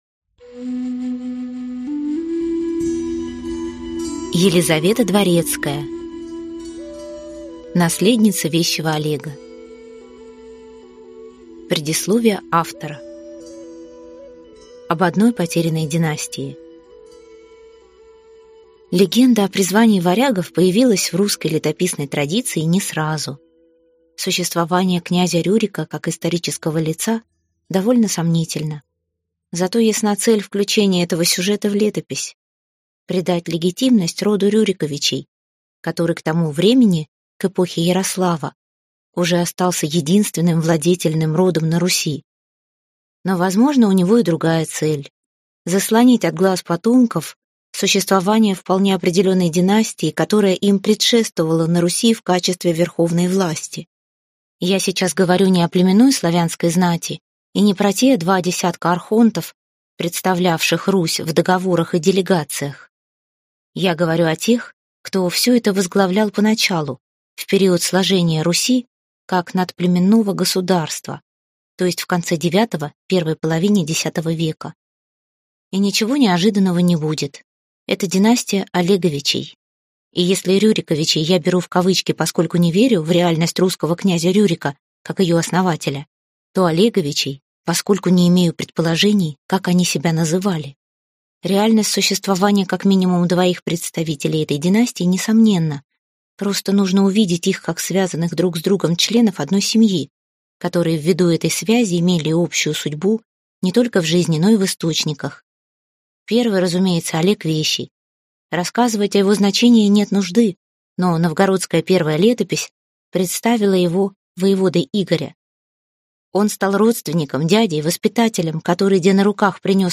Аудиокнига Наследница Вещего Олега | Библиотека аудиокниг